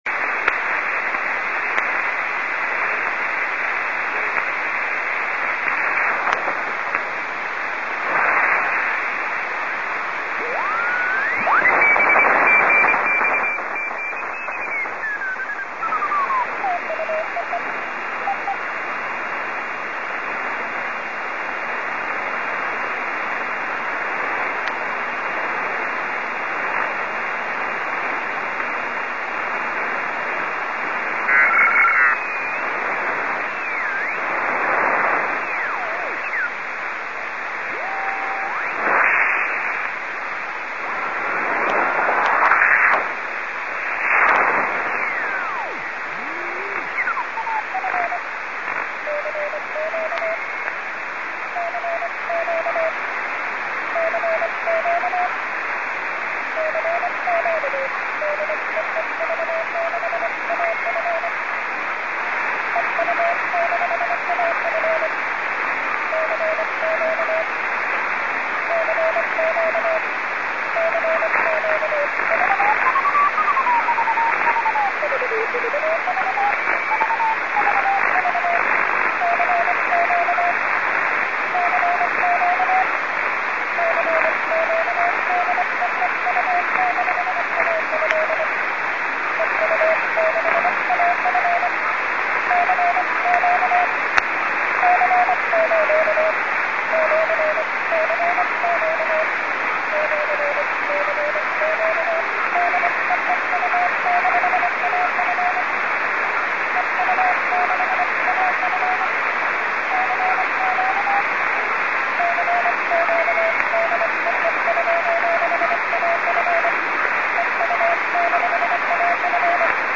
На записи зафиксирована работа любительских радиостанций
Приёму создаёт помехи какая-то наземная радиостанция